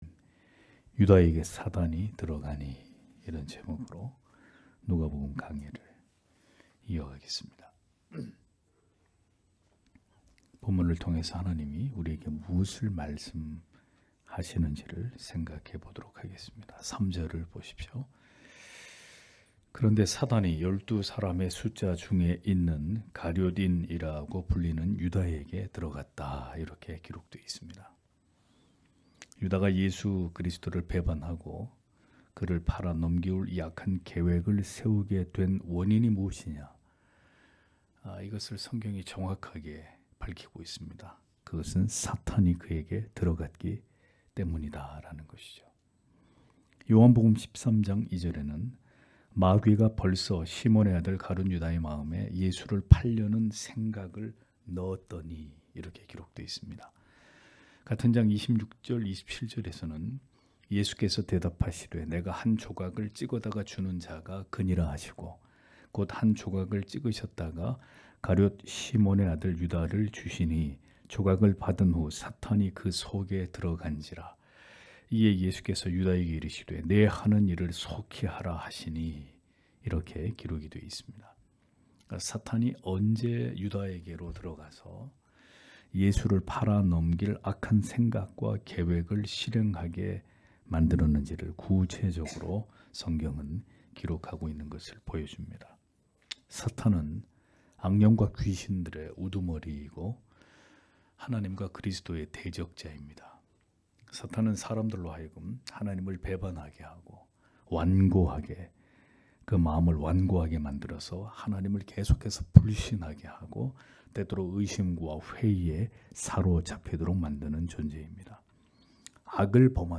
금요기도회 - [누가복음 강해 161] '유다에게 사단이 들어가니' (눅 22장 3- 6절)